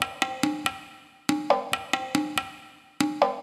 140_perc_3.wav